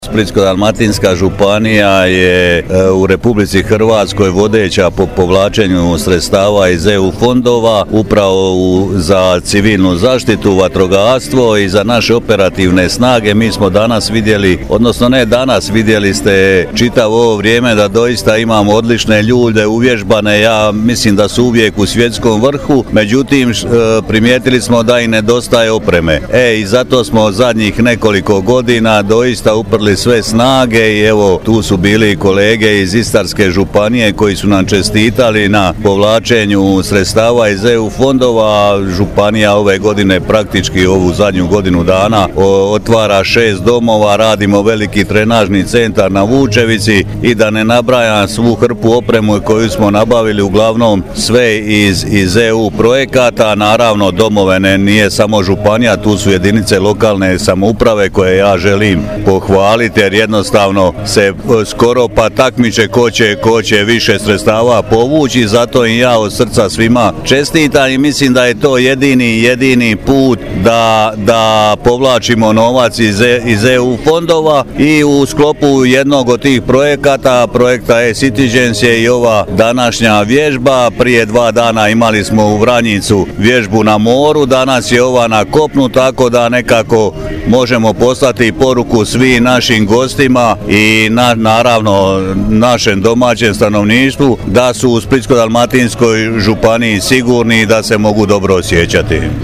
Cilj pokazne vježbe je vrednovanje postupaka operativnih snaga sustava civilne zaštite u slučaju nastanka potresa istaknuo je načelnik stožera CZ SDŽ Damir Gabrić: